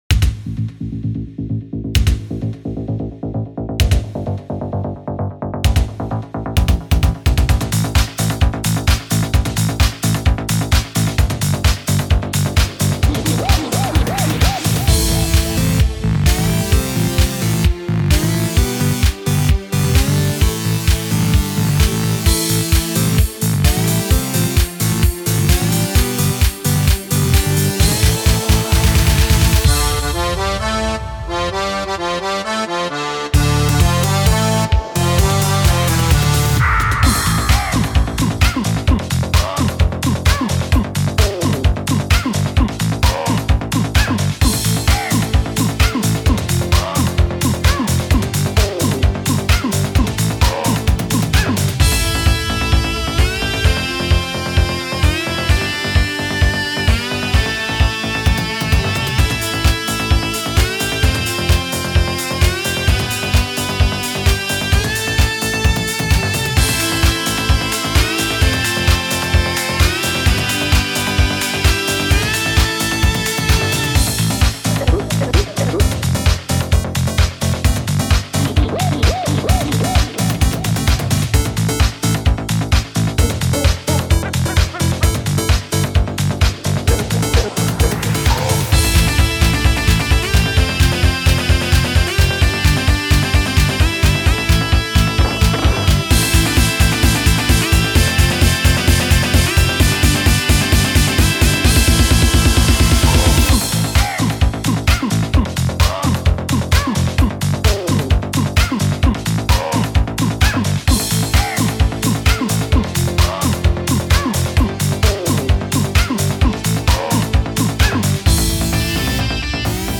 a fast, rocky remix